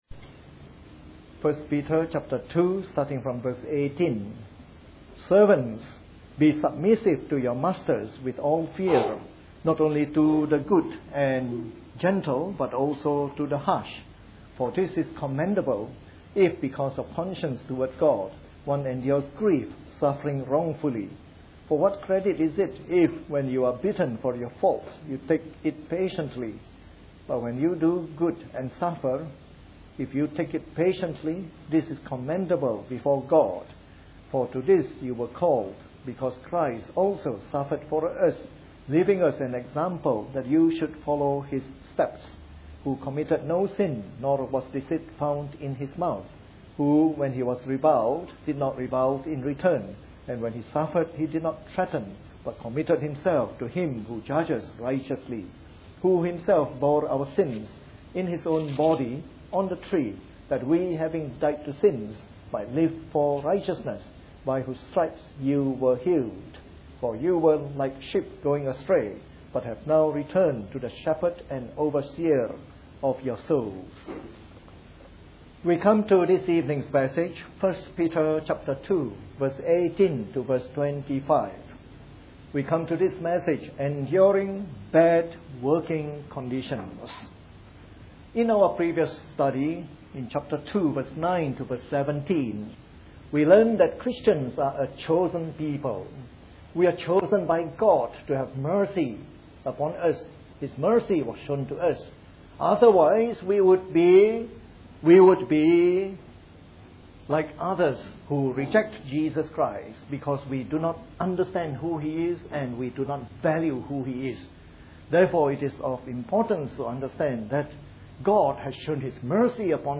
Part of our new series on “The Epistles of Peter” delivered in the Evening Service.